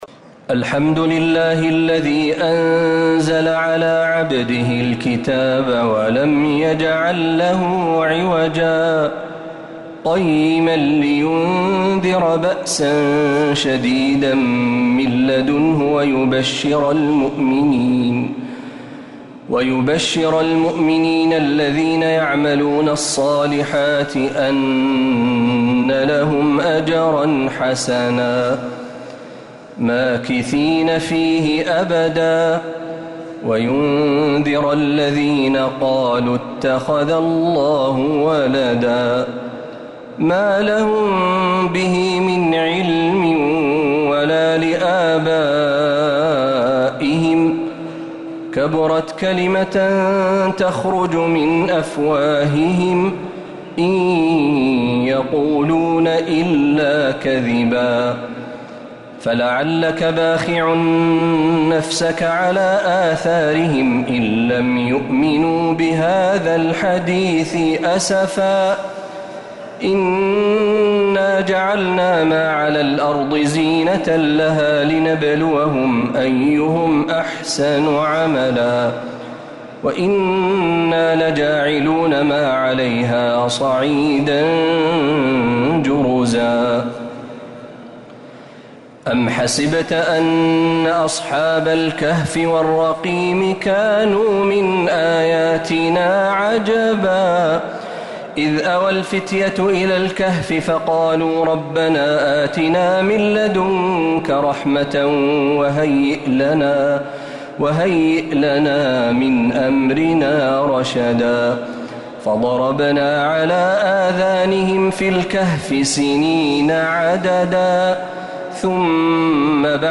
سورة الكهف كاملة من الحرم النبوي | رمضان 1446هـ